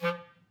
Clarinet
DCClar_stac_F2_v3_rr1_sum.wav